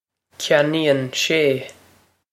Pronunciation for how to say
kyan-een shay